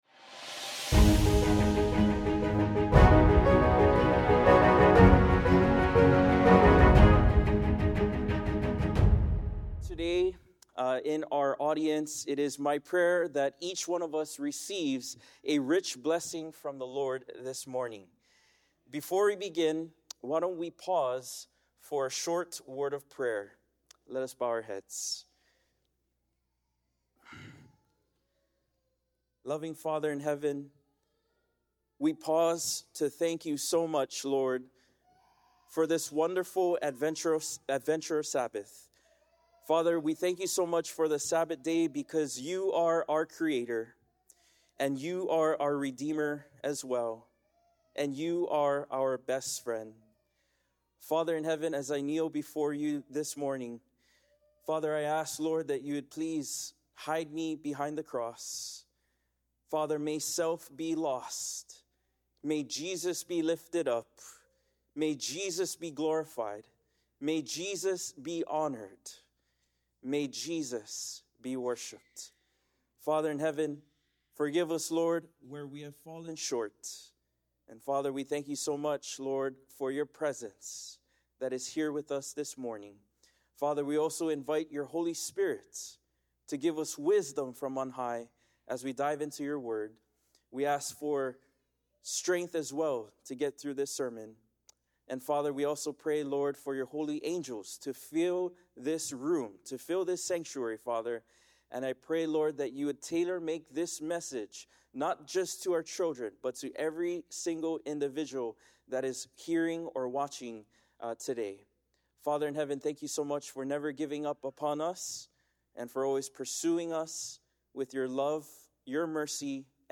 Sermon Archive – Sacramento Central Seventh-day Adventist Church